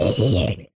zombiedeath.ogg